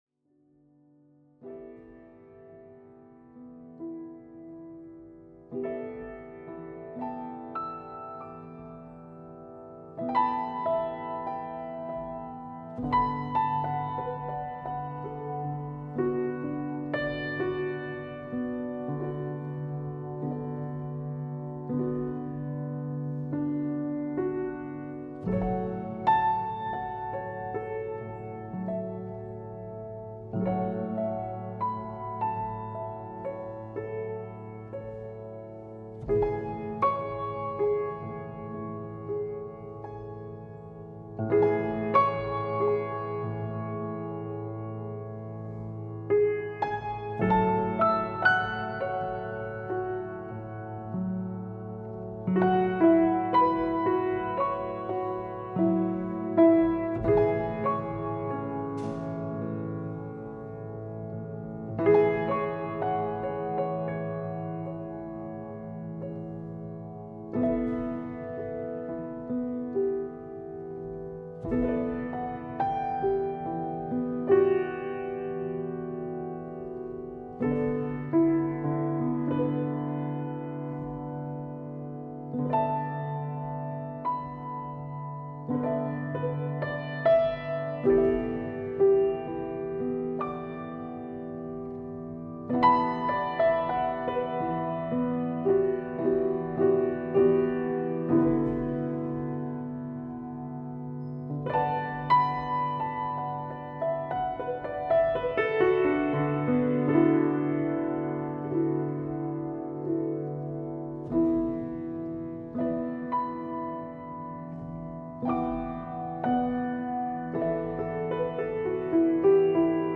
Ambient.mp3